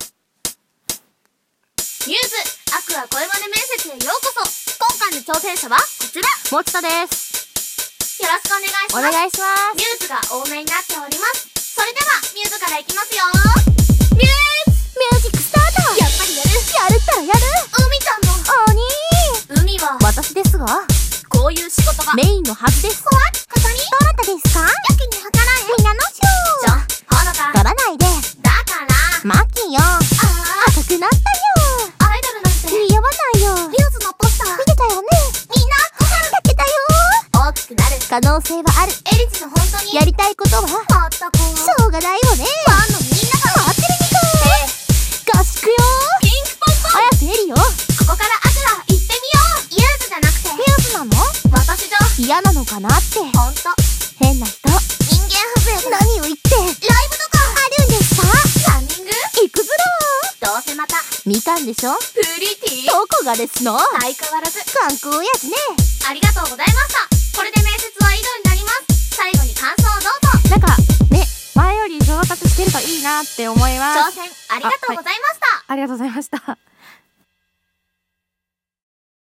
µ’sAqours声真似面接